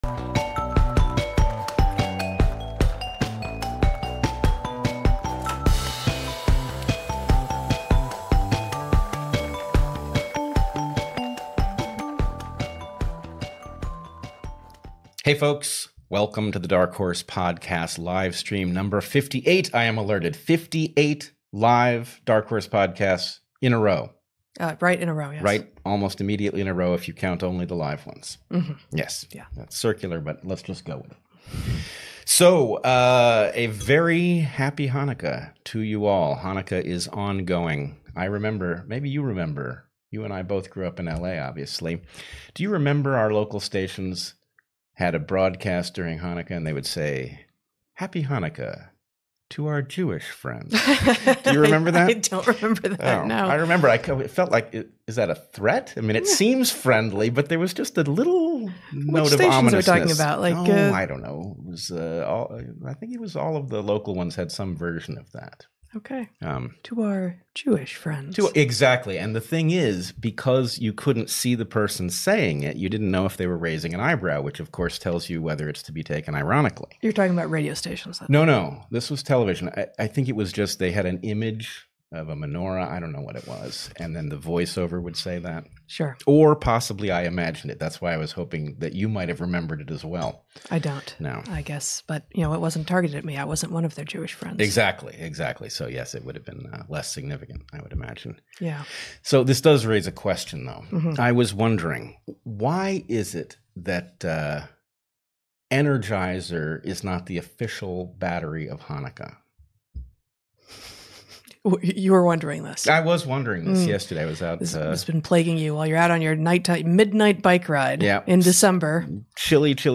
In this 58th in a series of live discussions with Bret Weinstein and Heather Heying (both PhDs in Biology), we discuss the state of the world through an evolutionary lens. In this episode, we discuss the COVID-19 vaccines, and what questions you would want to answer in order to understand them: Are they safe?